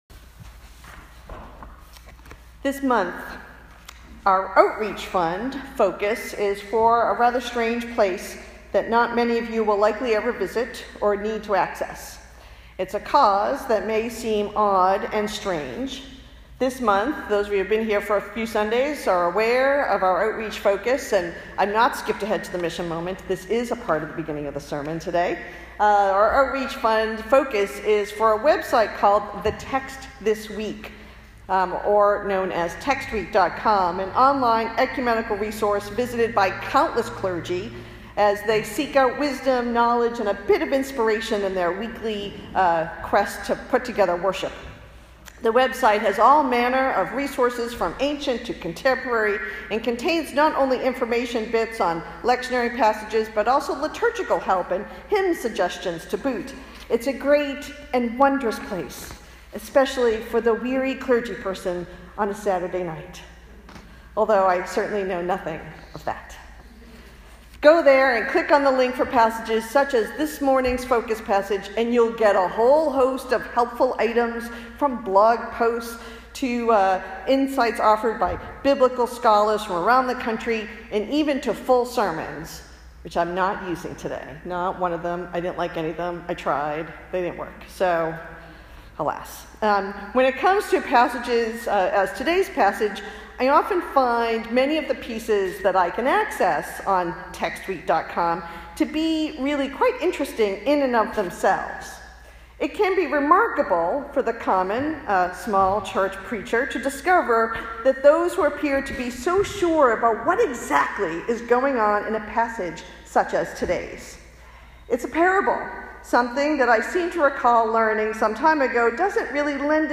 Old South Congregational Church, United Church of Christ
Related Posted in Sermons (not recent)